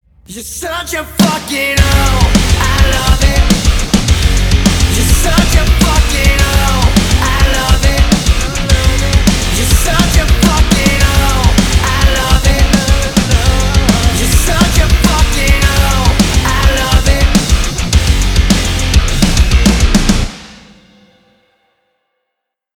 • Качество: 320, Stereo
громкие
мощные
Metalcore
Cover
Post-Hardcore
альтернативный метал